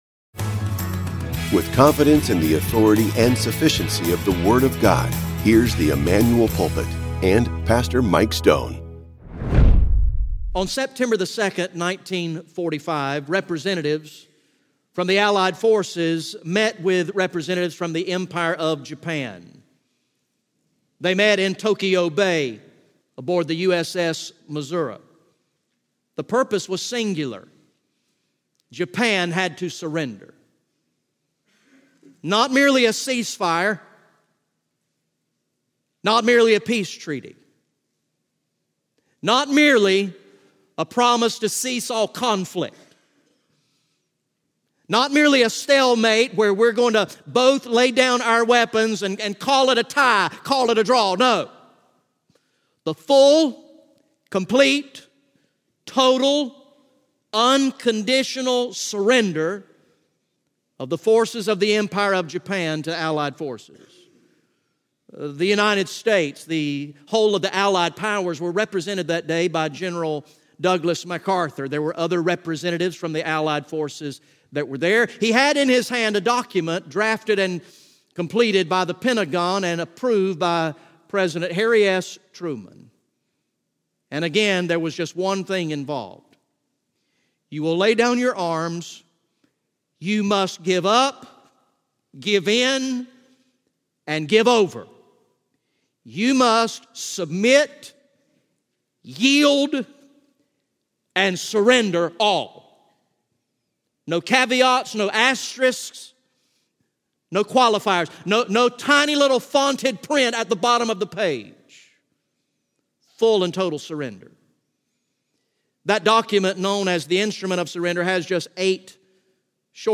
GA Message #38 from the sermon series titled “King of Kings